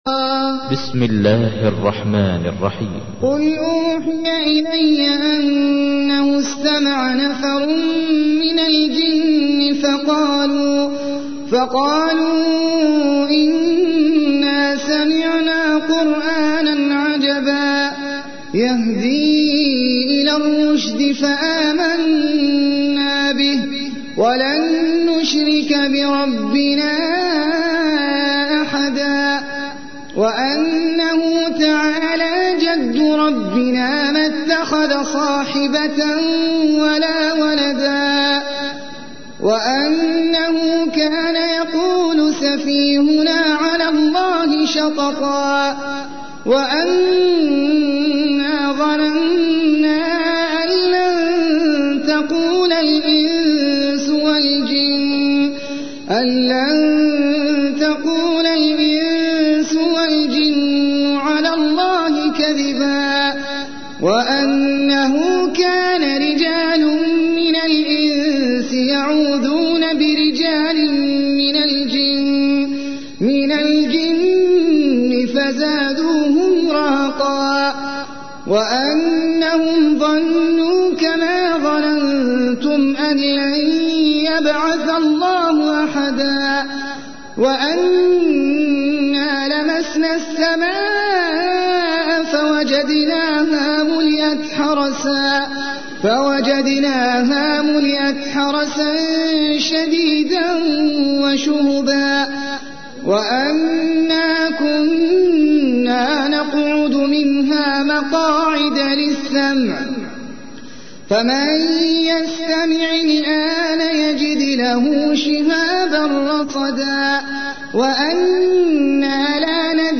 تحميل : 72. سورة الجن / القارئ احمد العجمي / القرآن الكريم / موقع يا حسين